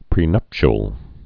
(prē-nŭpshəl, -chəl)